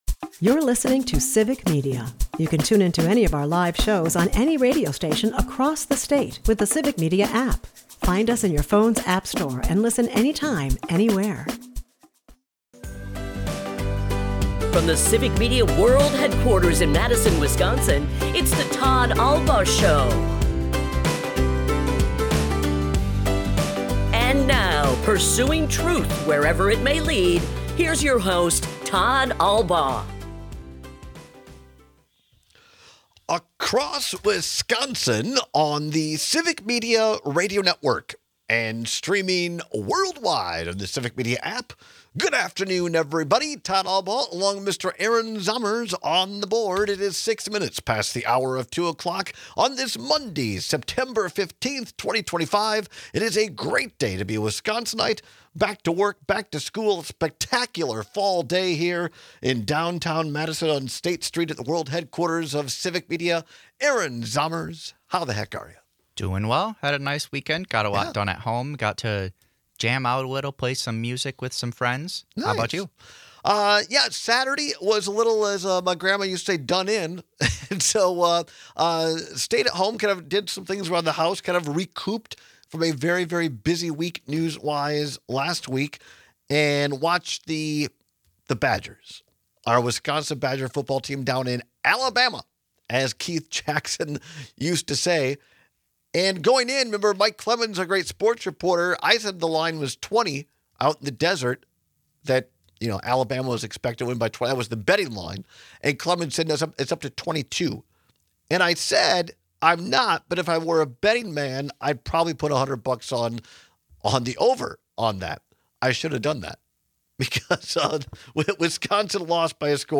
Guests: Dale Schultz